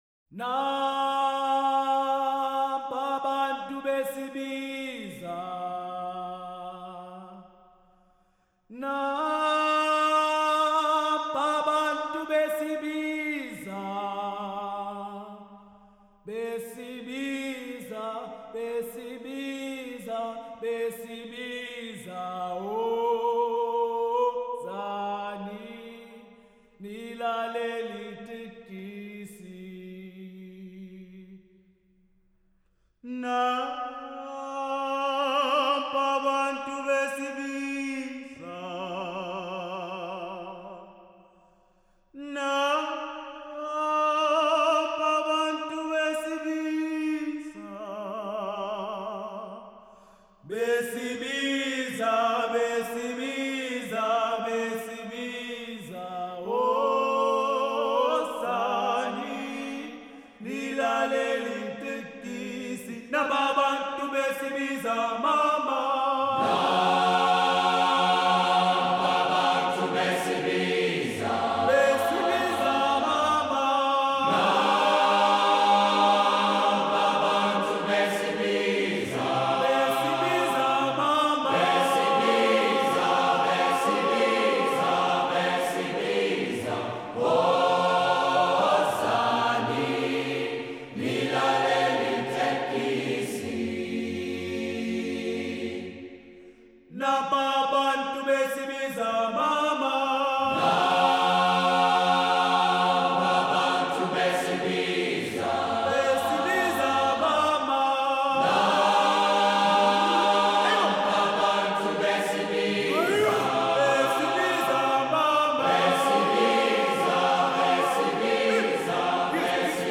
traditional South African songs